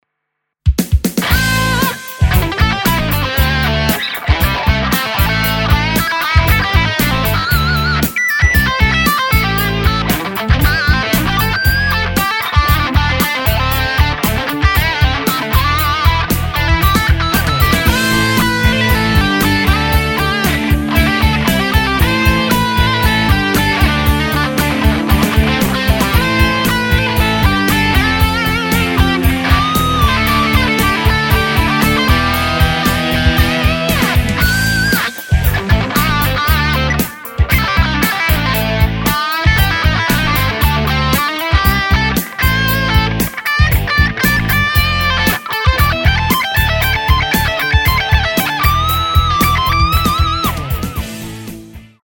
Modern_Fusion_Blues - �� �������